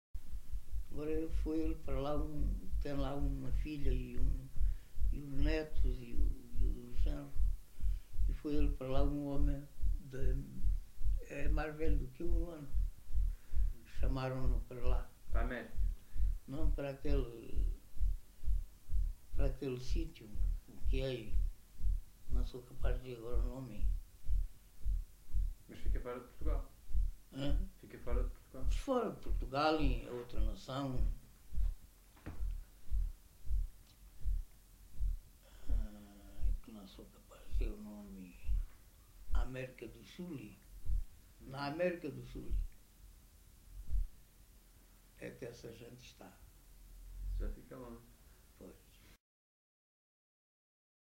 LocalidadeCarrapatelo (Reguengos de Monsaraz, Évora)